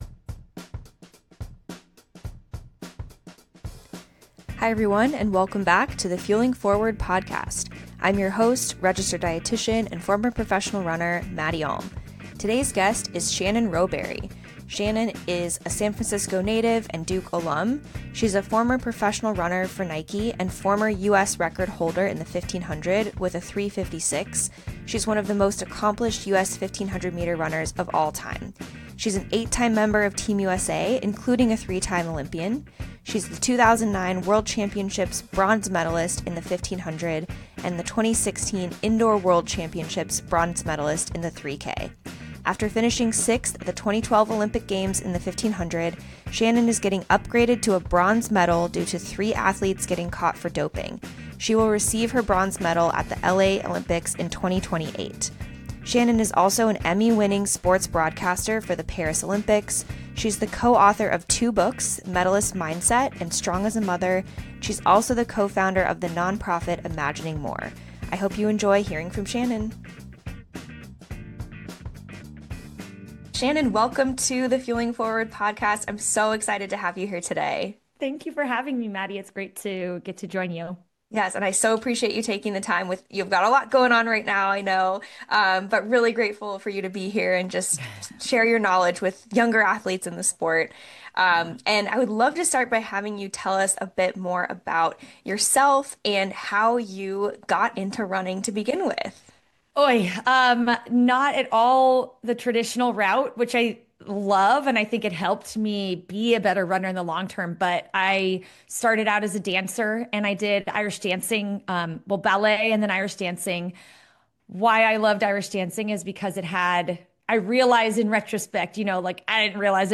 This episode is an interview with American middle distance running great, Shannon Rowbury.